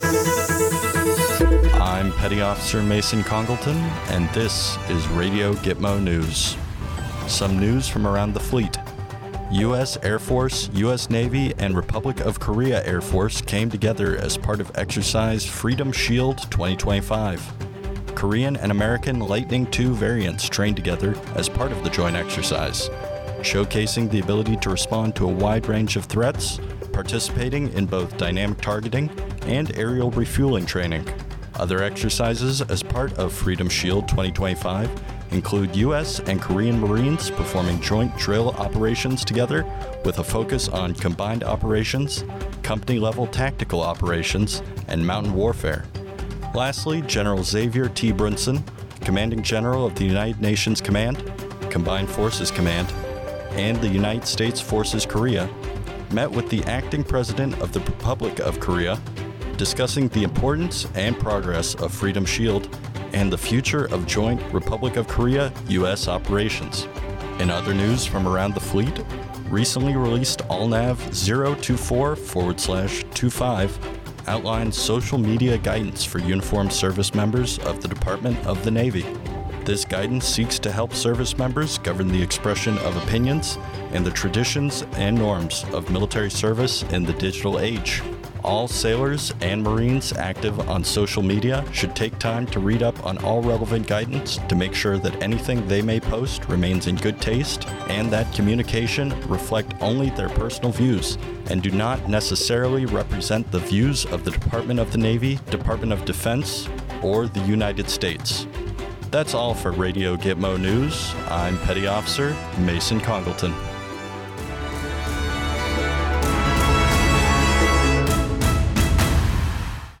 A radio newscast informing Naval Station Guantanamo Bay residents of exercise Freedom Shield 2025 and ALNAV 024/25 which gives guidance on personal social media usage for service members of the Department of the Navy.